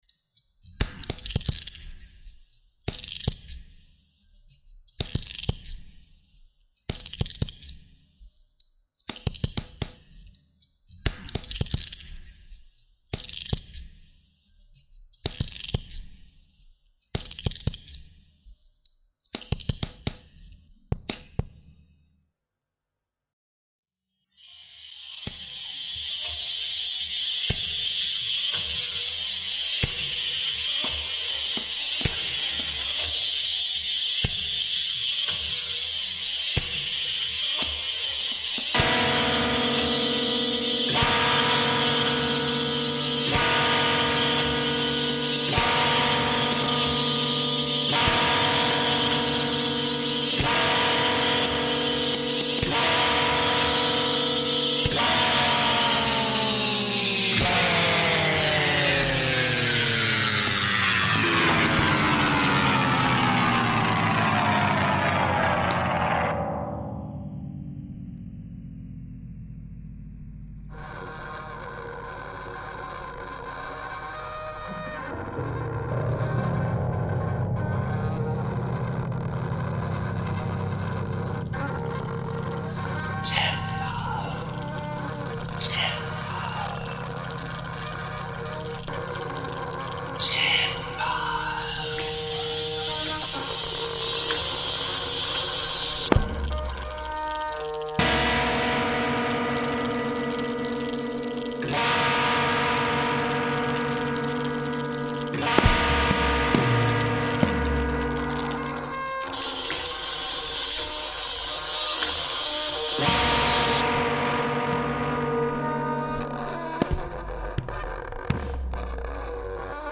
a collection of new sound works inspired by items from the Smithsonian Museums’ collections